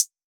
Closed Hats
Hi-Hat 2.wav